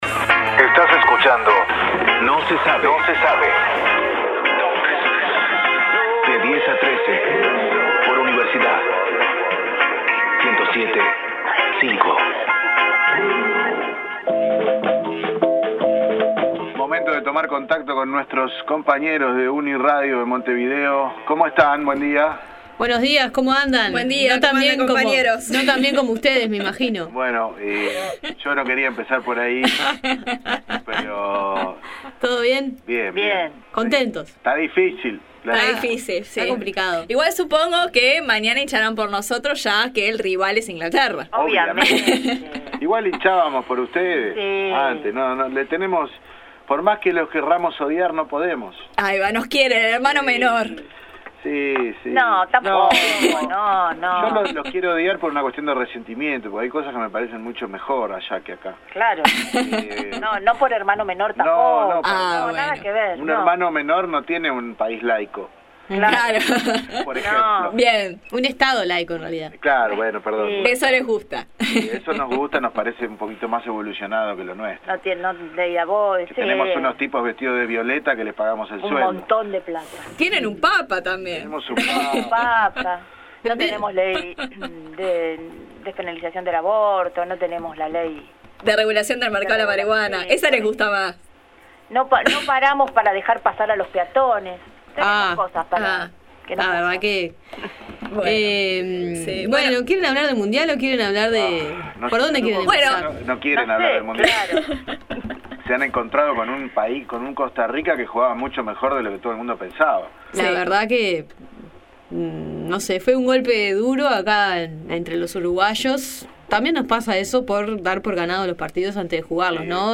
En el dúplex de este miércoles con los compañeros de No Se Sabe de Radio Universidad Nacional de La Plata conversamos sobre lo que nos dejaron los primeros partidos de las selecciones uruguaya y argentina en el Mundial Brasil 2014 y la vuelta de Luis Suarez para enfrentar a la selección de Inglaterra donde Uruguay se juega todas las chances de seguir en la Copa del Mundo.